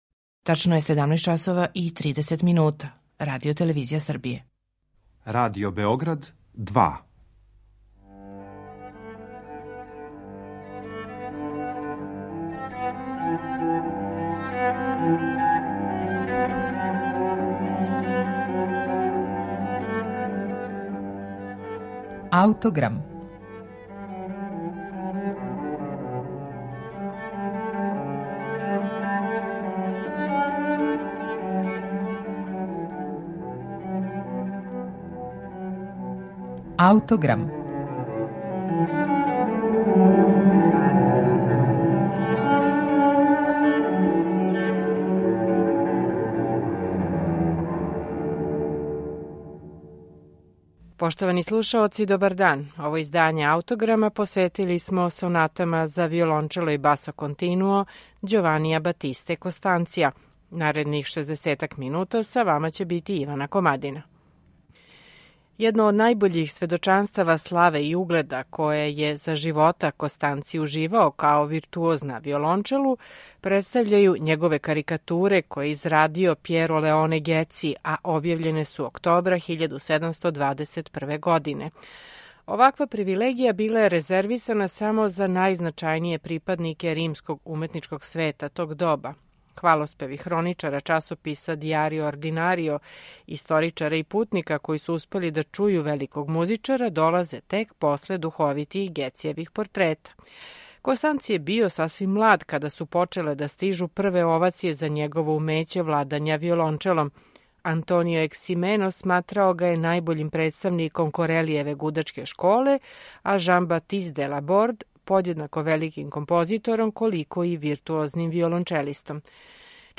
виолончело
Сонате за виолончело и басо континуо
архилаута
чембало